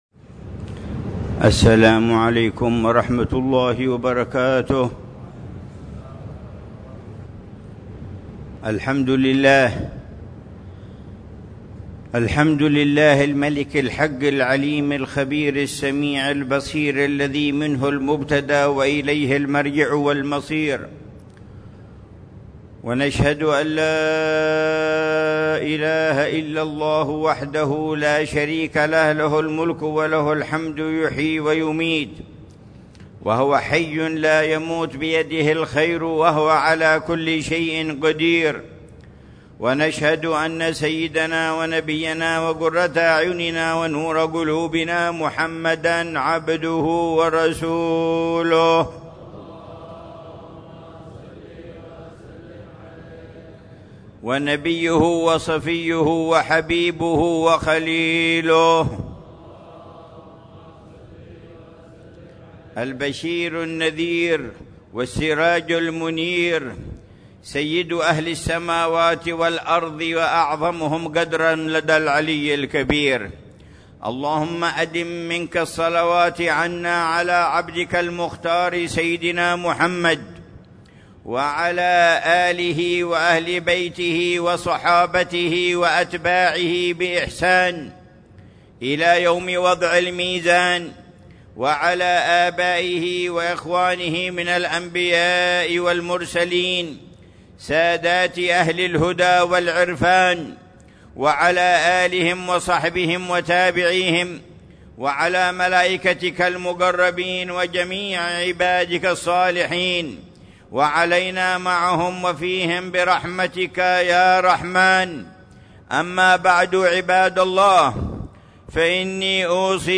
خطبة الجمعة
في جامع الروضة بحارة الروضة، عيديد، مدينة تريم